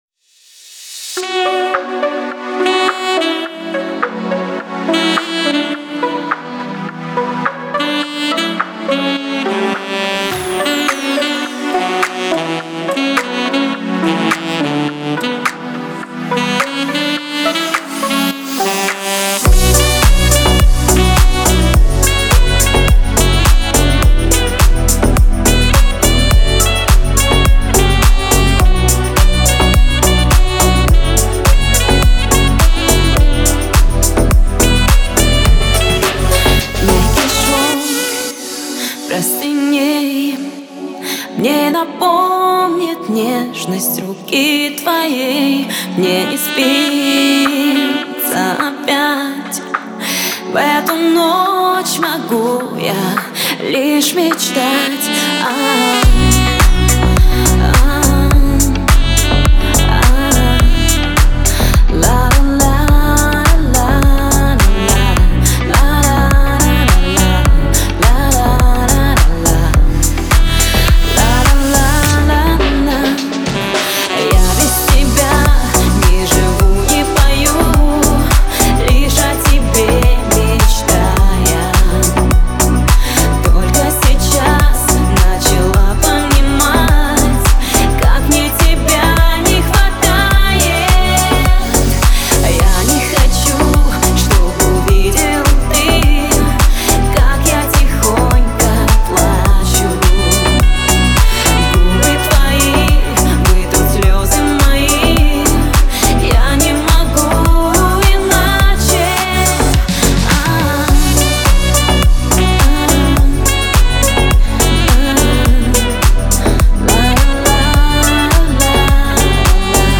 Мелодичные каверы
клубные ремиксы